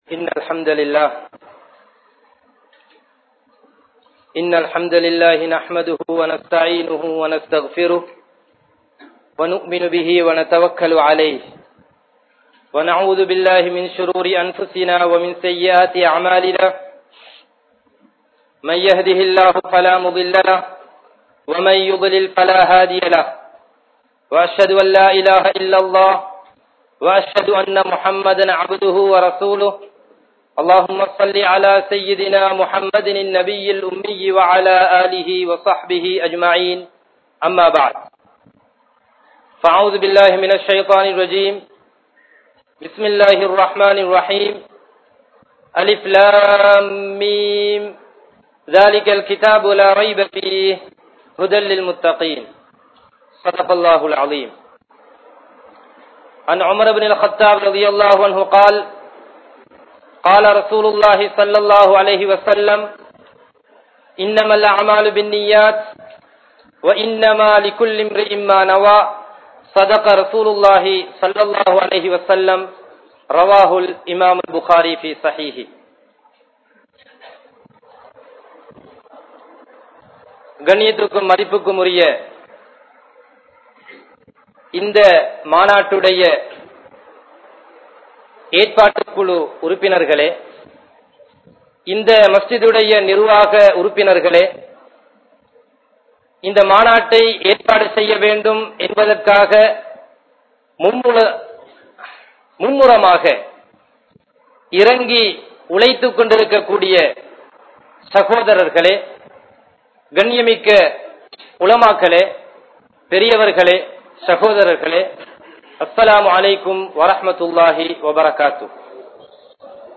Hijab Thelivu Maanaadu(02) (ஹிஜாப் தெளிவு மாநாடு (02)) | Audio Bayans | All Ceylon Muslim Youth Community | Addalaichenai
Majmaulkareeb Jumuah Masjith